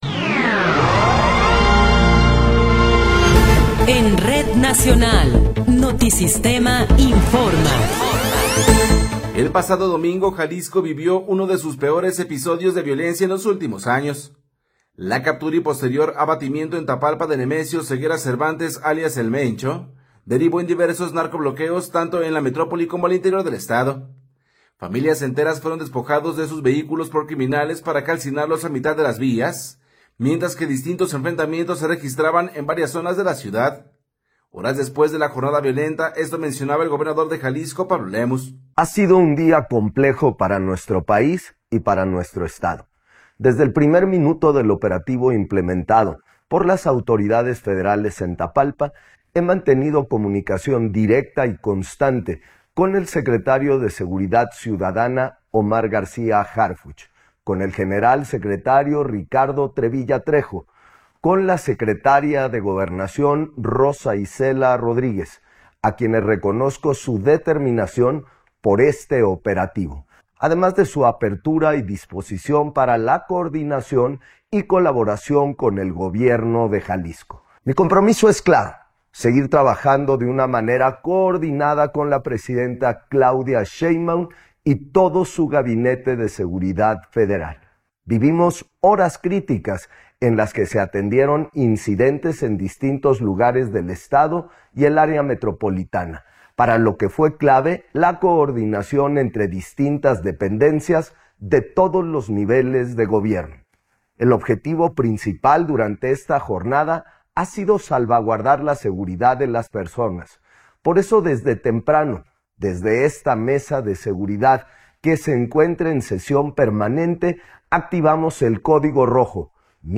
Noticiero 12 hrs. – 1 de Marzo de 2026
Resumen informativo Notisistema, la mejor y más completa información cada hora en la hora.